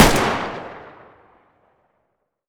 rpk47_distance_fire1.wav